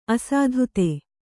♪ asādhute